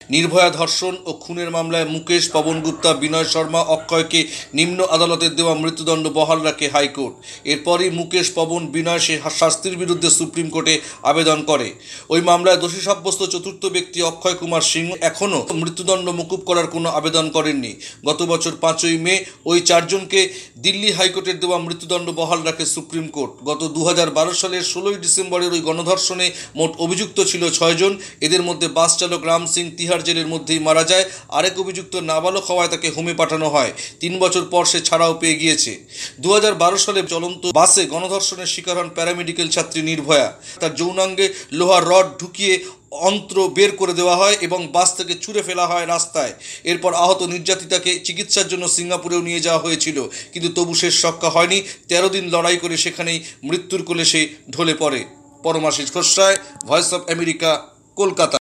কলকাতা থেকে
রিপোর্ট।